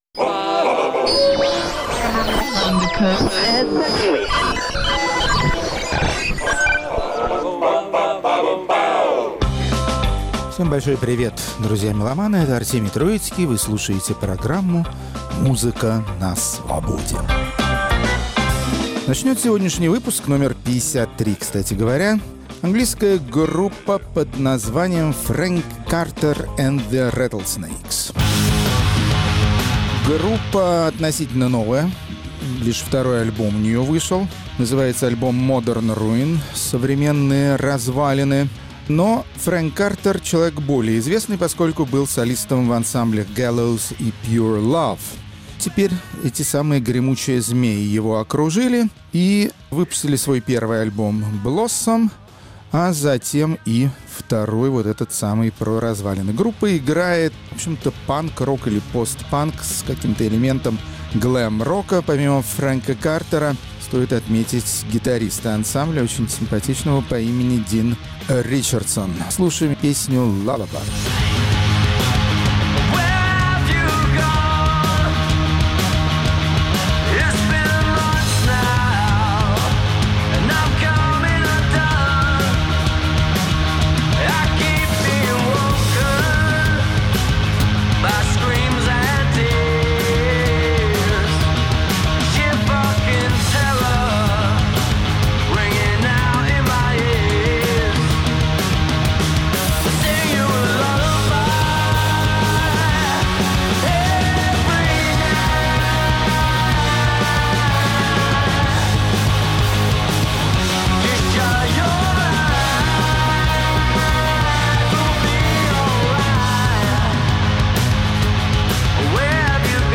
Хедлайнеры пятьдесят третьего выпуска программы "Музыка на Свободе" – так называемые сингеры-сонграйтеры из Канады и США, новое поколение американских исполнительниц. Рок-критик Артемий Троицкий проверяет на практике, есть ли будущее у американской песенной рок-традиции.